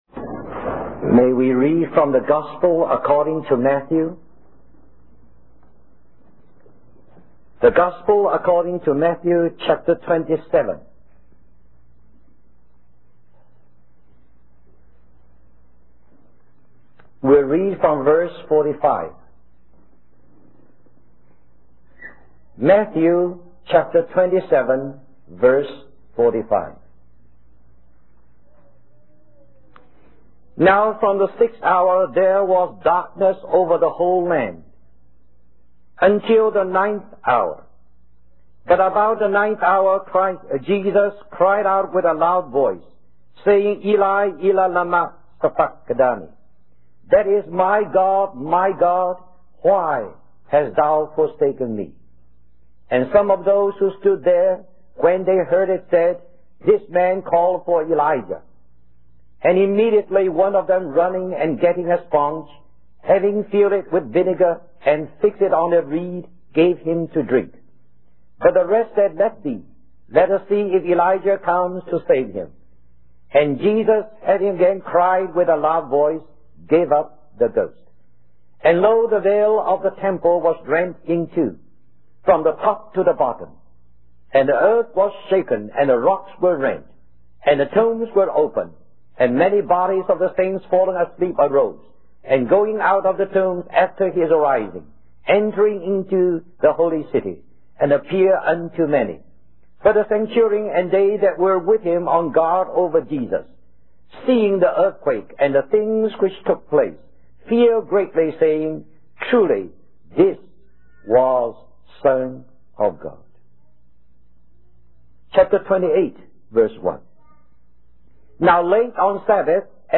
1977 Christian Family Conference Stream or download mp3 Summary In Matthew 27, we witness the profound events surrounding the crucifixion of Jesus Christ.